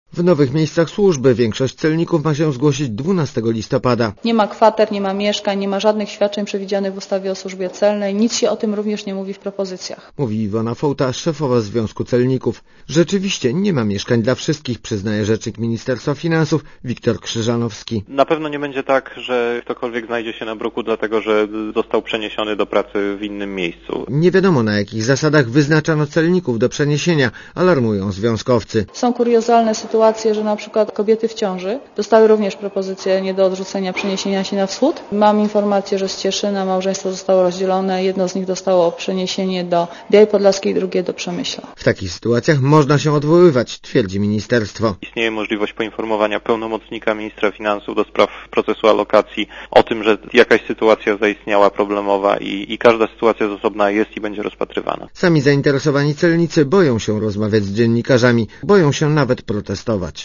Posłuchj relacji reportera Radia Zet (234 KB)